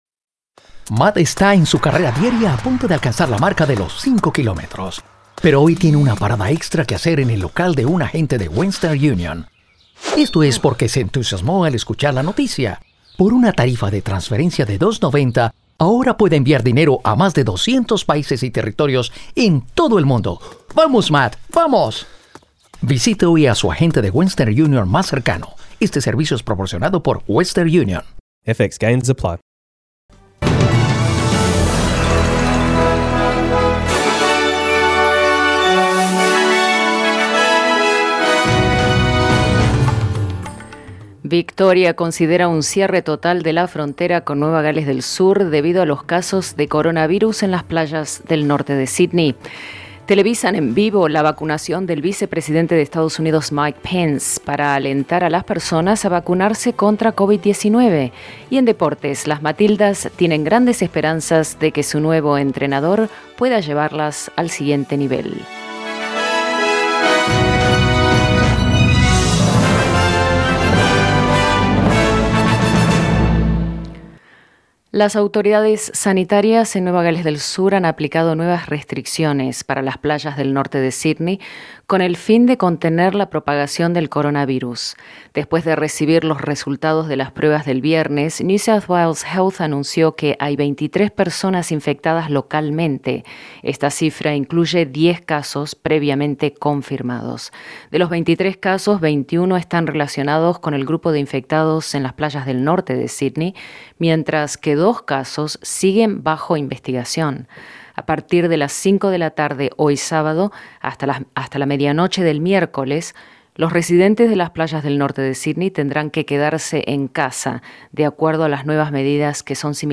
Noticias SBS Spanish | 19 diciembre 2020